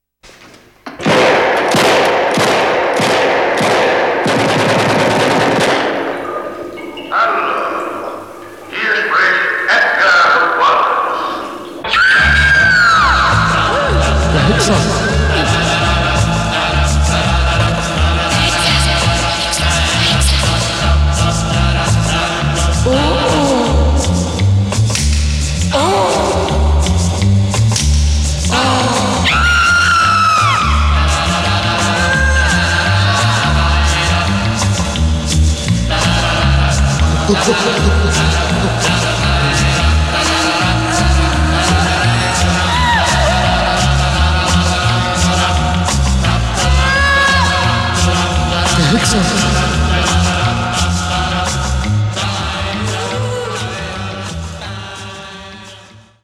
Originalmusik aus den deutschen Kult-Krimis